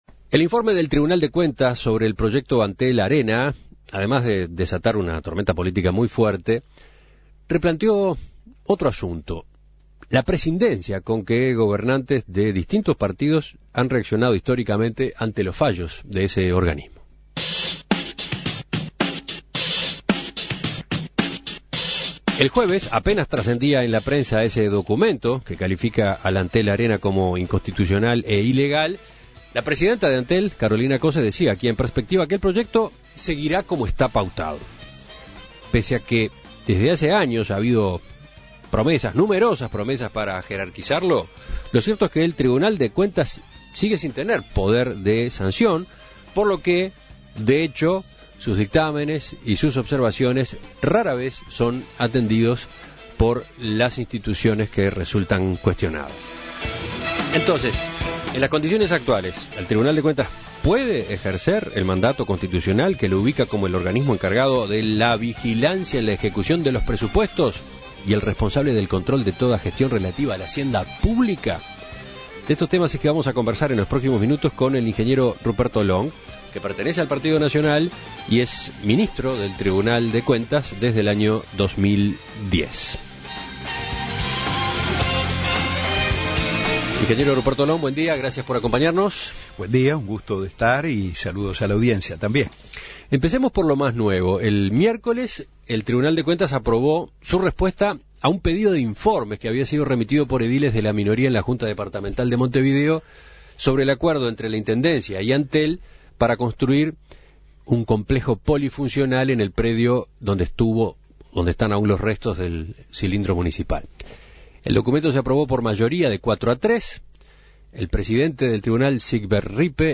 Escuche la entrevista a Ruperto Long